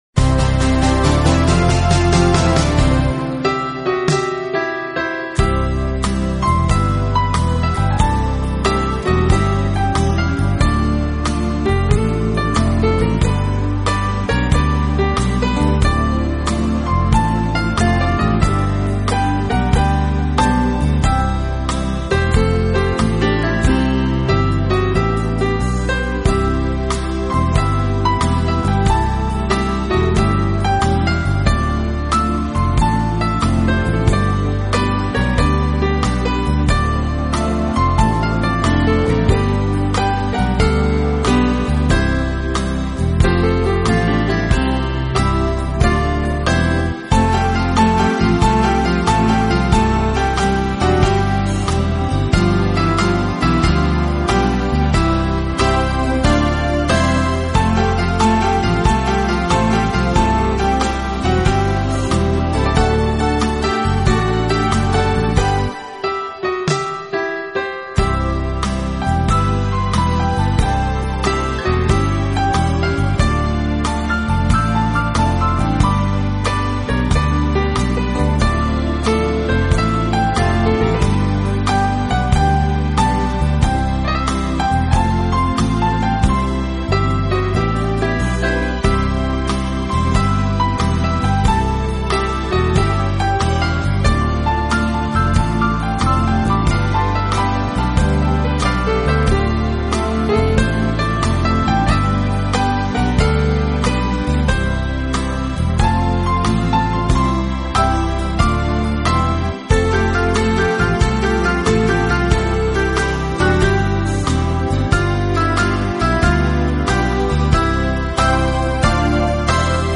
【浪漫钢琴】
钢琴演奏版，更能烘托出复古情怀，欧美钢琴大师深具质感的演奏功力，弹指
本套CD全部钢琴演奏，